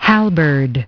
Транскрипция и произношение слова "halberd" в британском и американском вариантах.